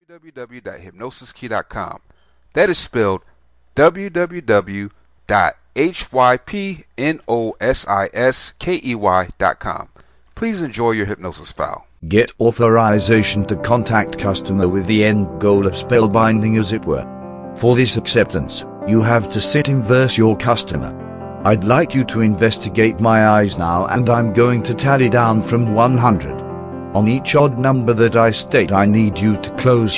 Odds Even Self Hypnosis Mp3